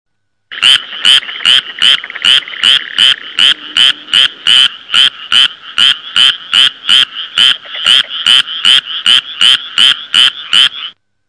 RANAS NOCHE FROGS
Ambient sound effects
Ranas_noche_frogs.mp3